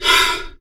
Heavy Breaths
BREATH5M.wav